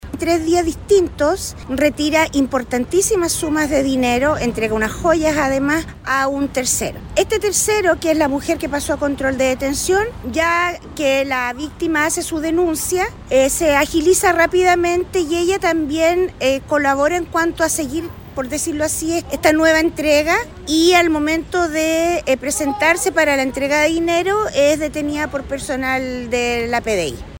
Fue la PDI que actuó entonces, frustrándose la tercera entrega, siendo detenida la mujer formalizada por estafa, como informó la fiscal jefe de Concepción, Mariana Iturrieta.